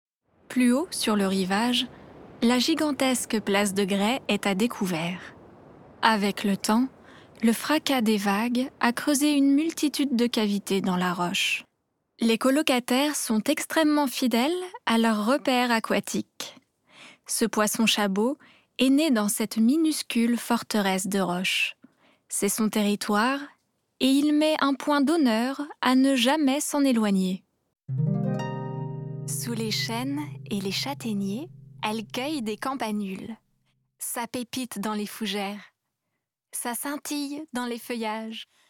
Démo voix off narrations